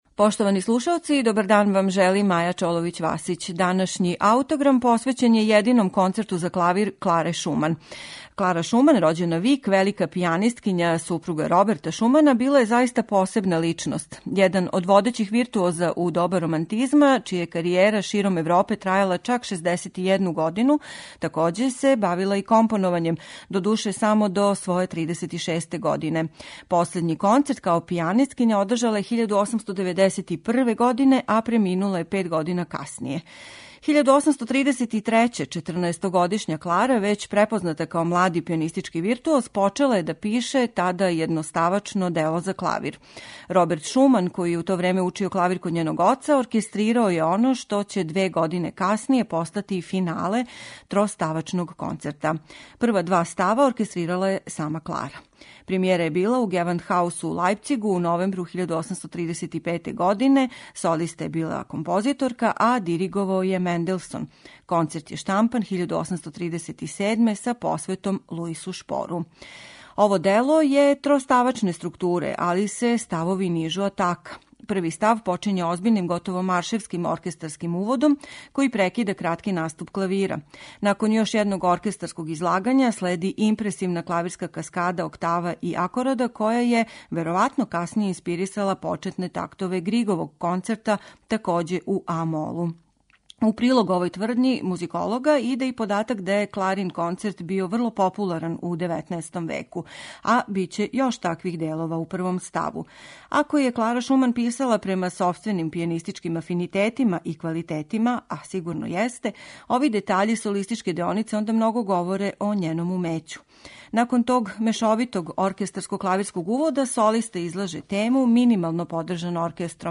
Премијера концерта, чија се три става нижу без паузе и који плени лакоћом, неоптерећујућим виртуозитетом и младалачким полетом, била је у Гевандхаусу у Лајпцигу 1835. године.